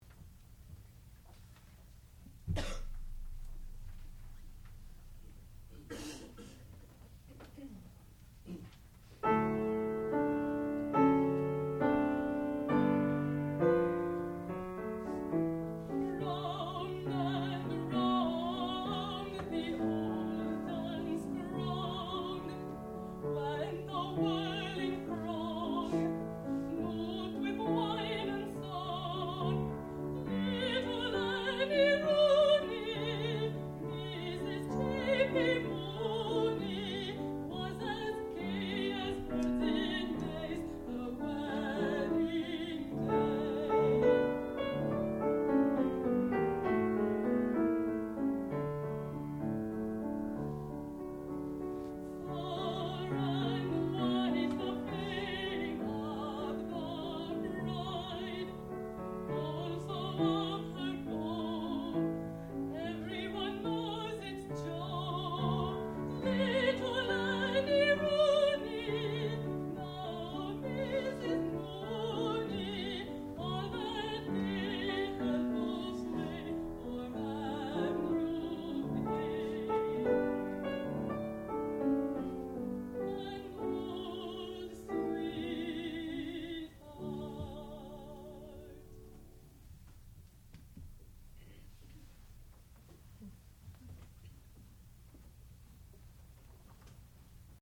sound recording-musical
classical music
piano
mezzo-soprano
Master's Recital